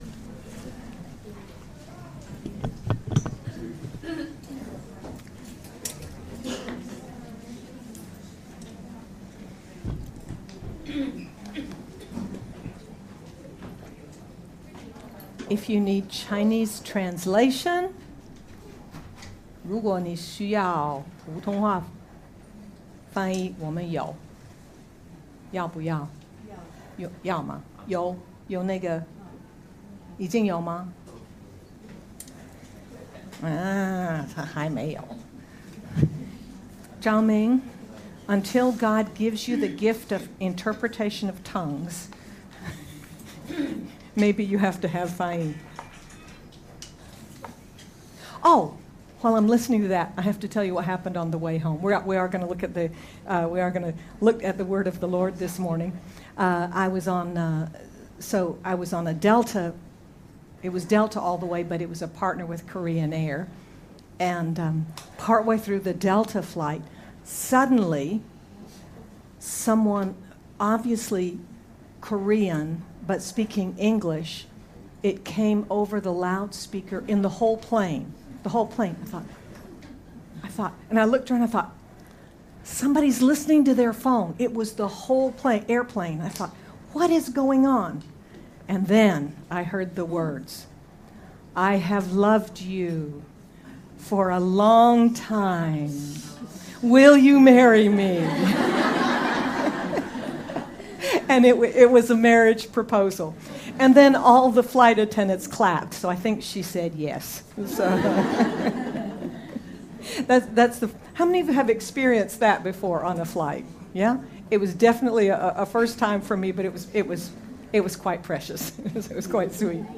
Jul 07, 2024 What You Should Know About the Holy Spirit—II MP3 SUBSCRIBE on iTunes(Podcast) Notes Discussion Conclusion of the message on three essentials we see about the Holy Spirit on the Day of Pentecost and Cornelius in Caesare. Sermon by